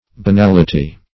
Banality \Ba*nal"i*ty\, n.; pl.